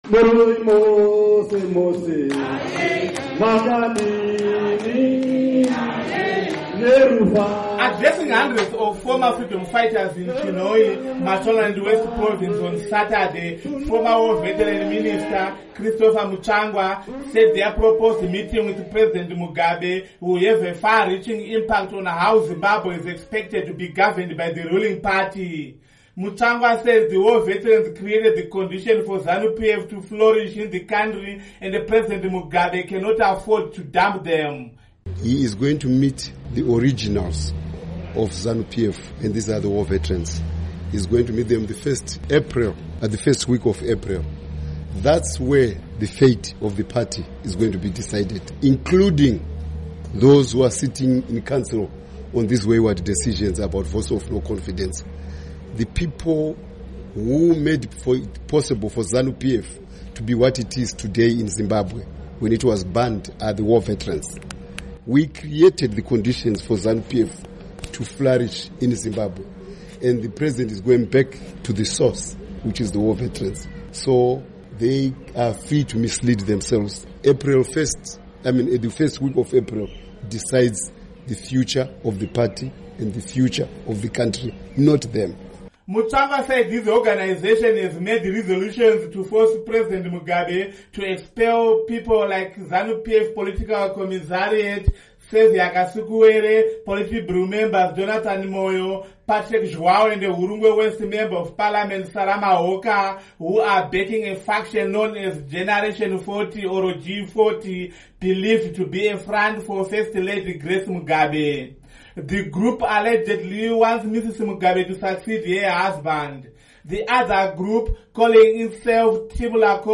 Addressing hundreds of former freedom fighters in Chinhoyi, Mashonaland West province on Saturday, former War Veterans Minister, Christopher Mutsvangwa, said their proposed meeting with President Mugabe will have a far-reaching impact on how Zimbabwe is expected to be governed by the ruling party.